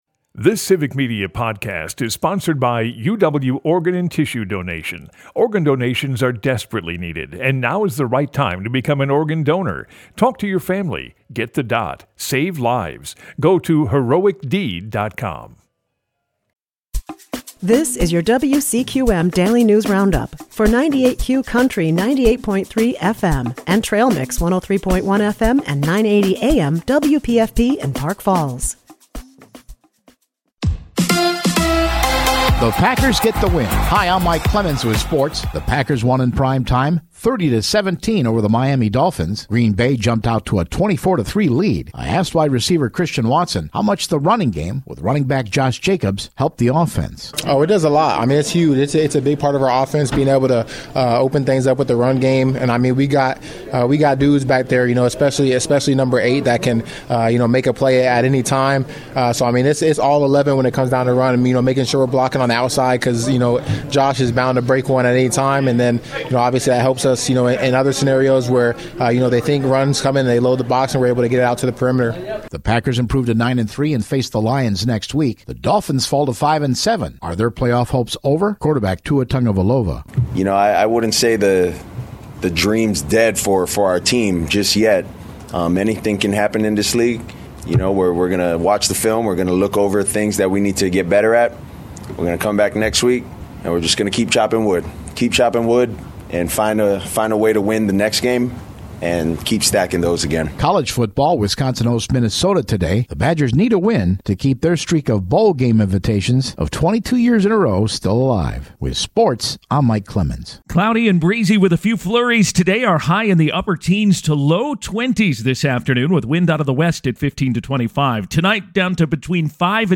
WCQM Friday News Roundup - 98Q News